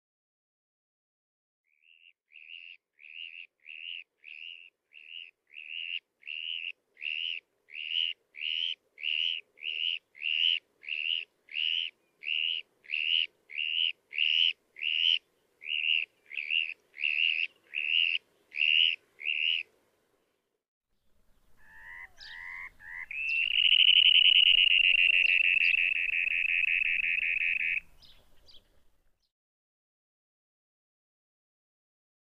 Pro romantické duše nebo ty, co se chtějí vědět o ptácích více, vydalo nakladatelství Alpress praktickou knížku Ptáci s připojeným CD se zvuky 96 nejběžnějších ptáků našich měst, zahrad a lesů.
JESPÁK OBECNÝ „Po sérii výhružného vrčení čurrrréér-ér-ér následuje dlouhý drnčivý trylek. V obou případech se jedná o zpěv.“